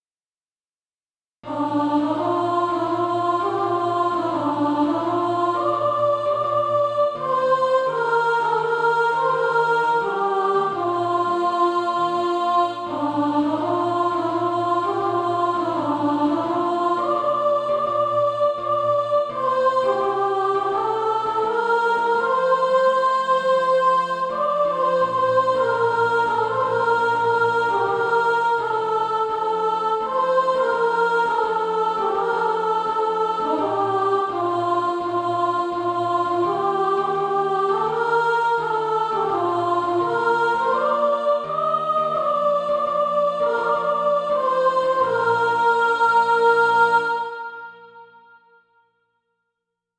Soprano Track.
Practice then with the Chord quietly in the background.